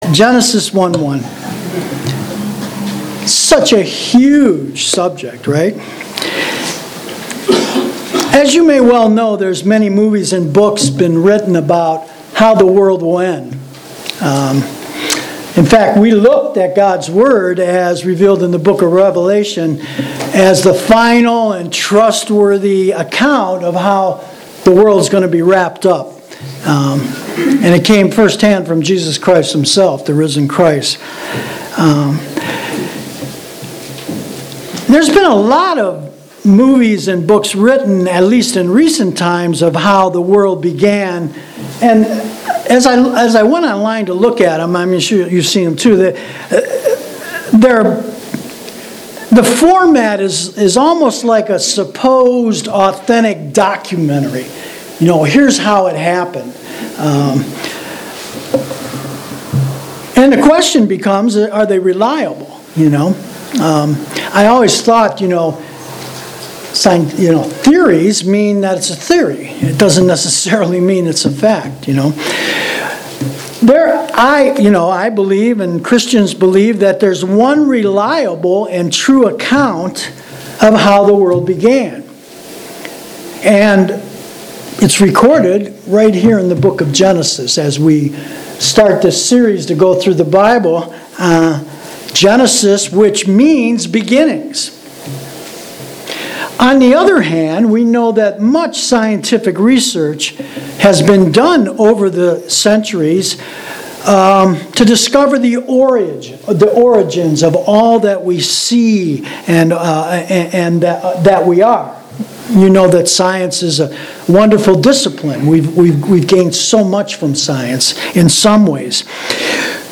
Grace Evangelical Bible Church » Sermons
October 21, 2018 (Sunday Morning Service)